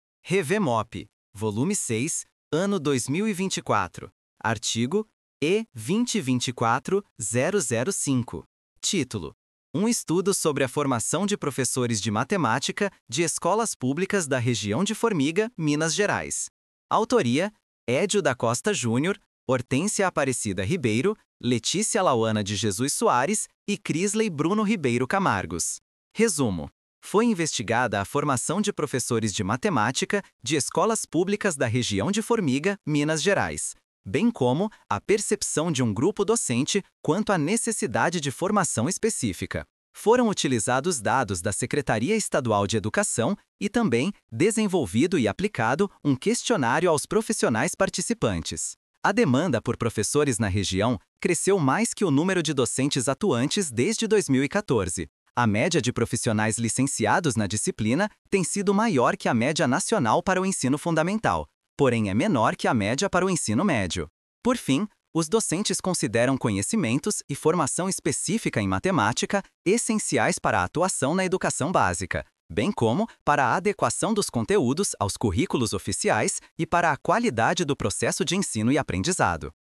Áudio do resumo